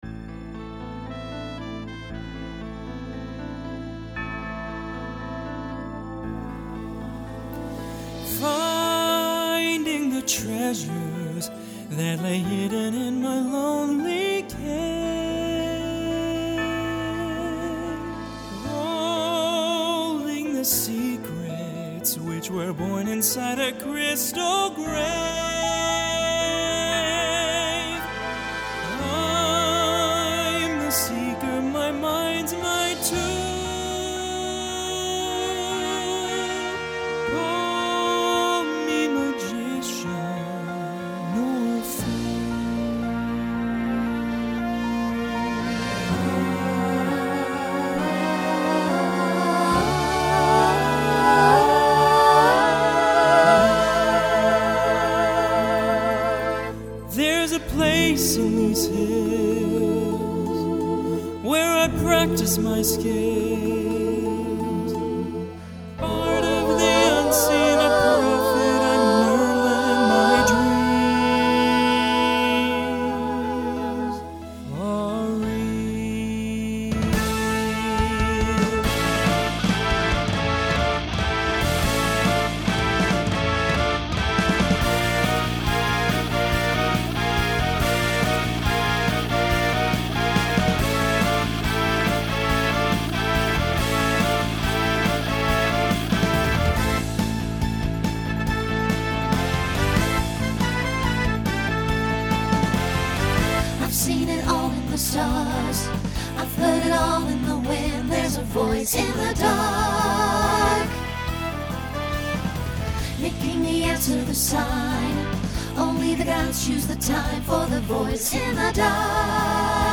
Genre Rock
Story/Theme Voicing SATB